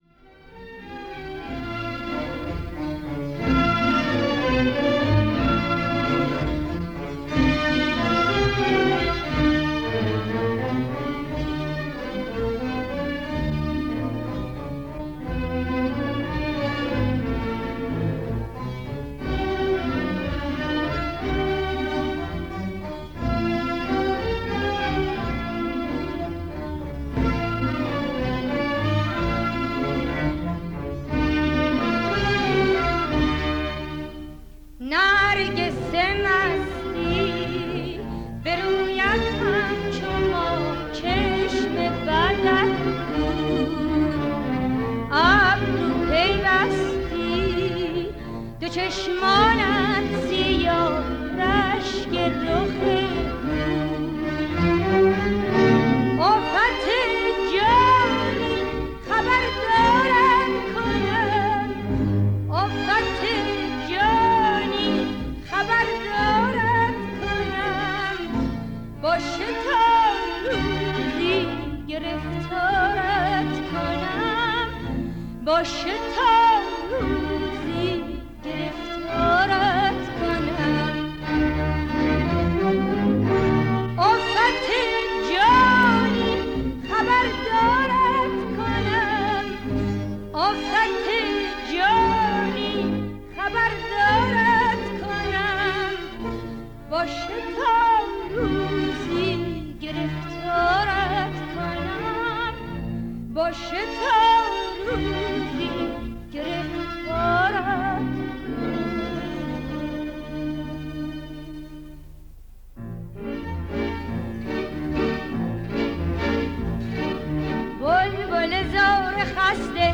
در مقام: ماهور